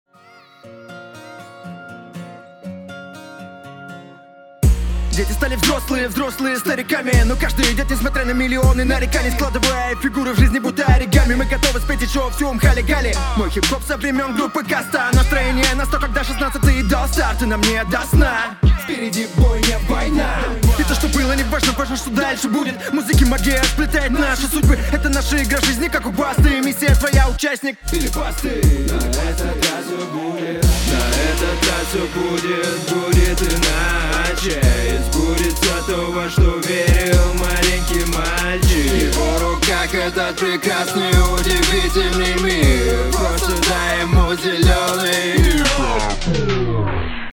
Подачу подтяни. Иногда жуешь слова. Припев в конце порадовал.